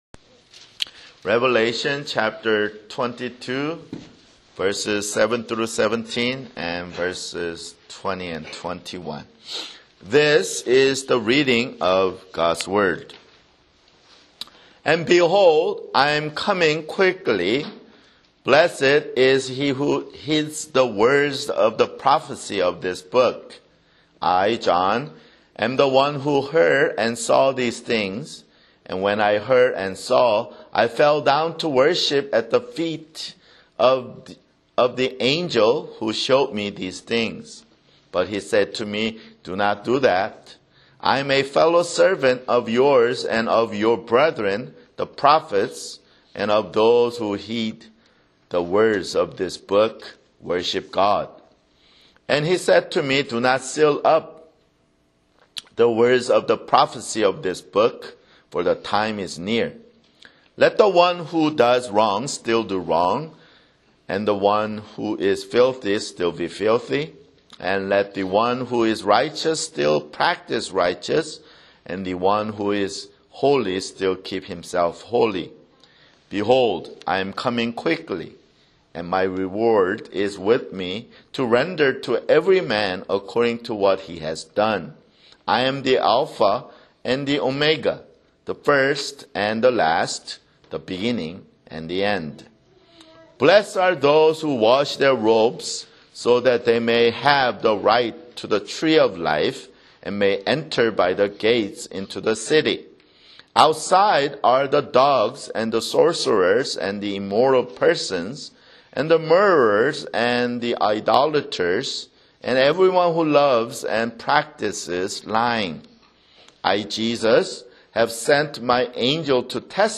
[Sermon] Revelation (93)